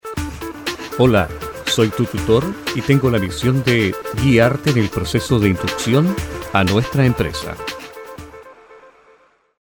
Soy un Locutor profesional chileno,de vasta trayecto ria en Emisoras de mi pais.
Sprechprobe: eLearning (Muttersprache):